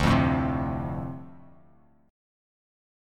Dm7 Chord
Listen to Dm7 strummed